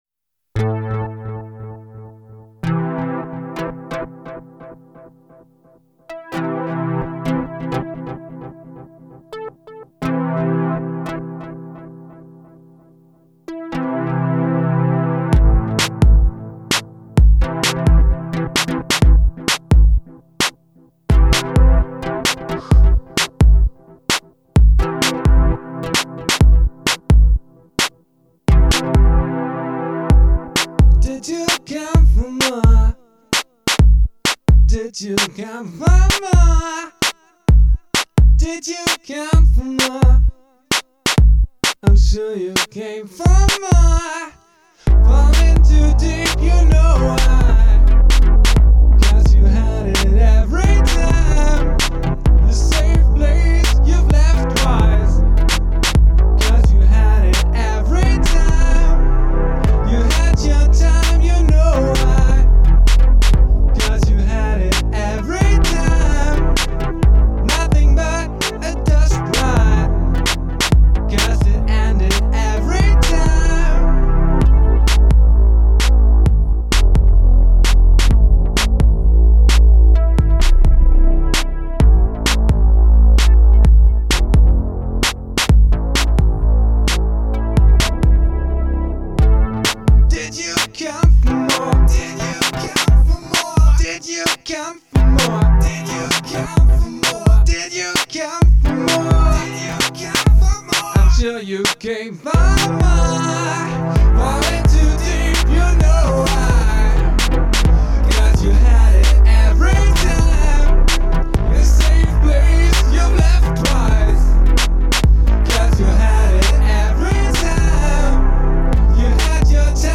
vocals, guitars, bass, loops, synth